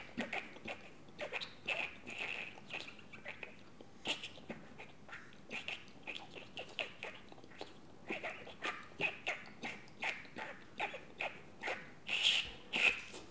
bruit-animal_01.wav